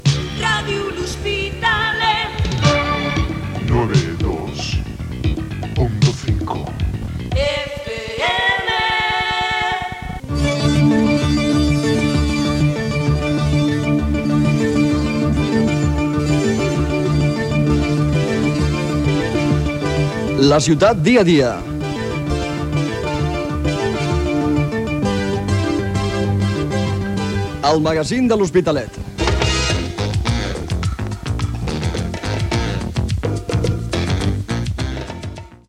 Identificació de l'emissora i del programa.
Informatiu